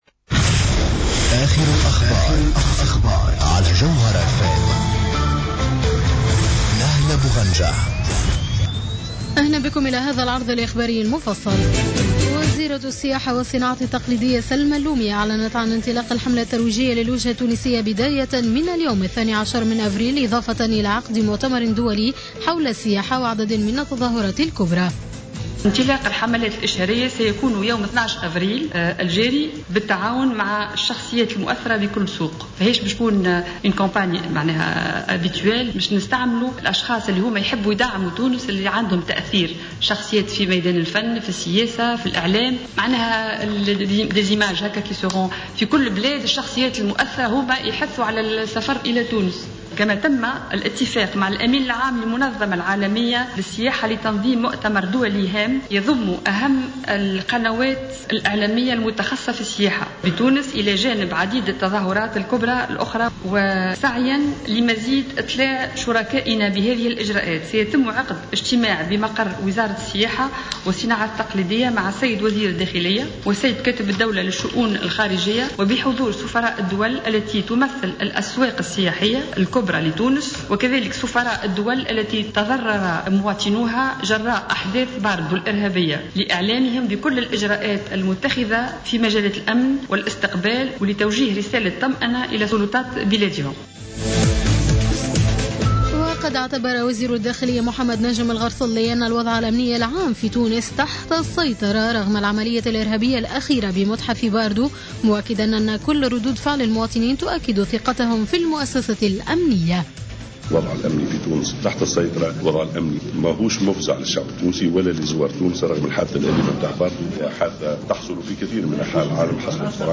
نشرة أخبار منتصف الليل ليوم الأحد 12 أفريل 2015